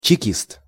A member of Cheka was called a chekist (Russian: чеки́ст, romanized: chekíst, IPA: [t͡ɕɪˈkʲist]
Ru-чекист.ogg.mp3